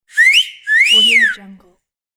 Loud Wolf Whistle Botão de Som